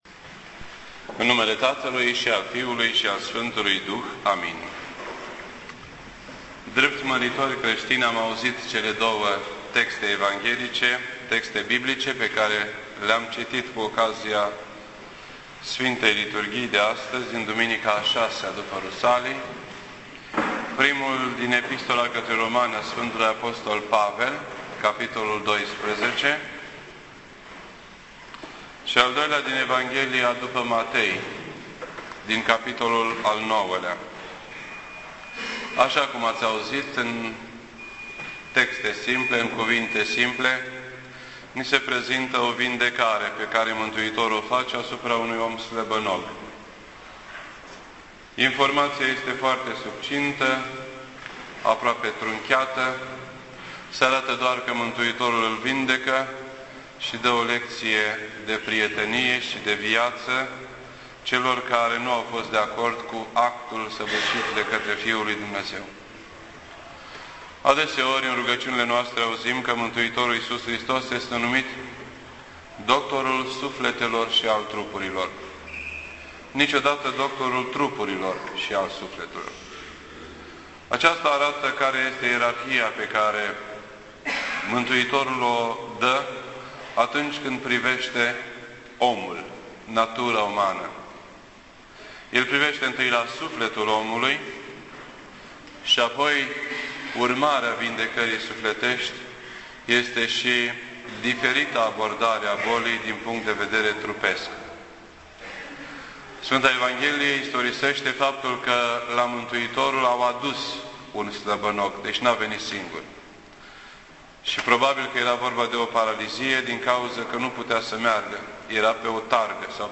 This entry was posted on Sunday, July 4th, 2010 at 9:01 PM and is filed under Predici ortodoxe in format audio.